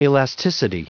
Prononciation du mot elasticity en anglais (fichier audio)
Prononciation du mot : elasticity